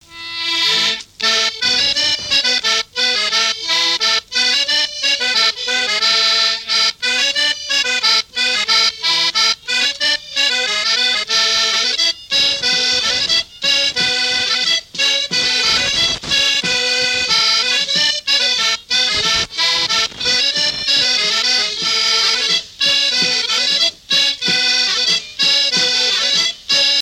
Chants brefs - A danser
danse : scottich trois pas
Pièce musicale inédite